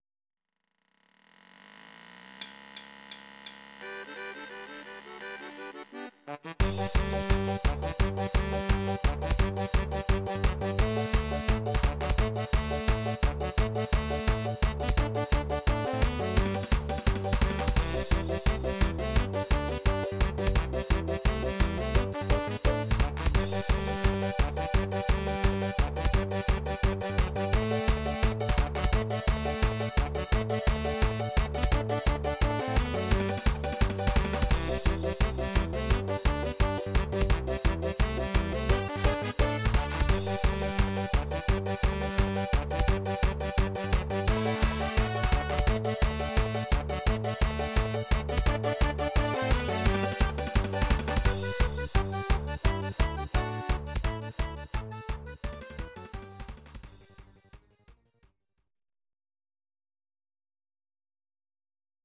Audio Recordings based on Midi-files
Pop, Dutch, 1990s